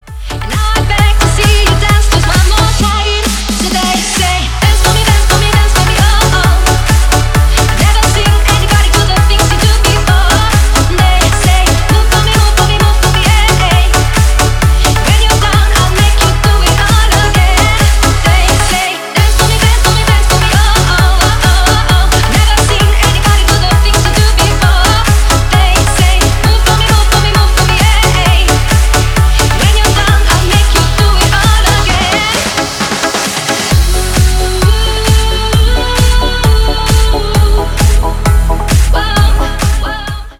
Ремикс
клубные # ритмичные